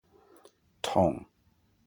Captions English Tonge Pronunciation